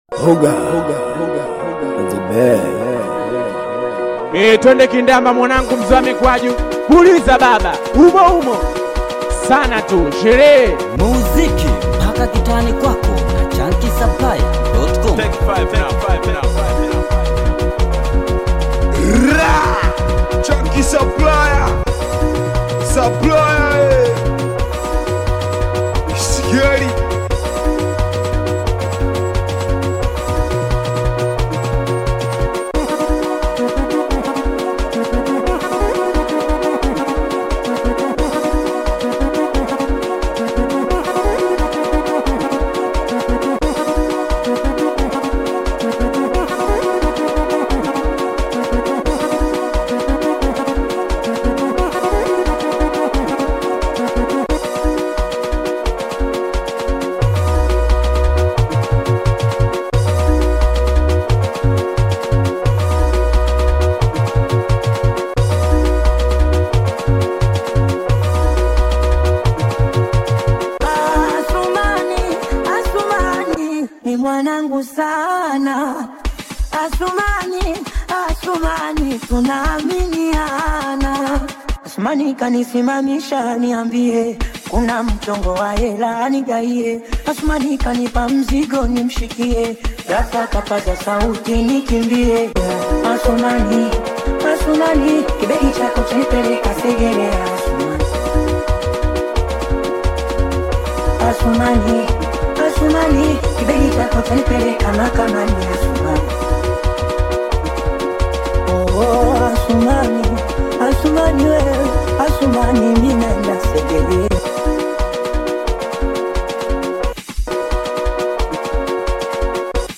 SINGELI BEAT